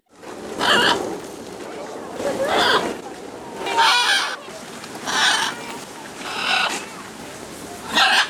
Bird Sounds
2. Scarlet Macaw